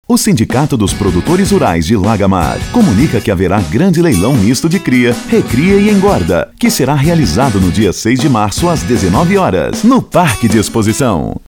INSTITUCIONAL :